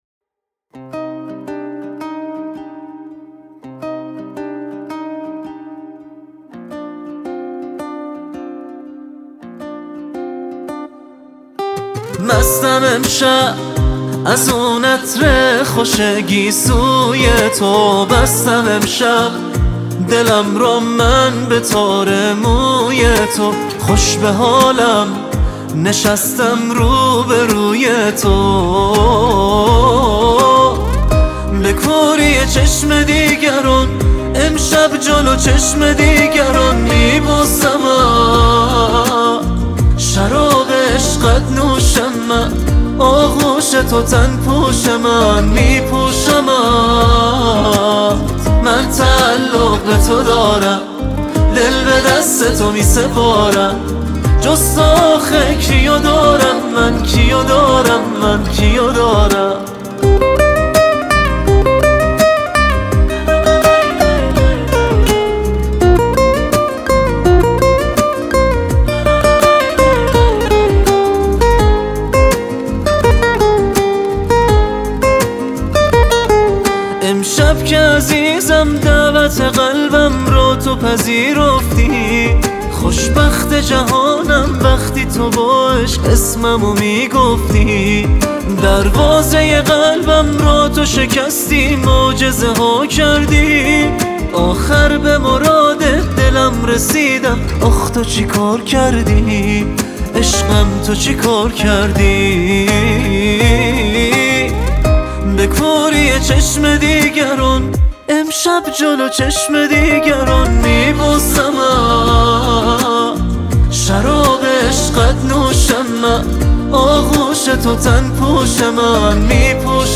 خواننده پاپ ایرانی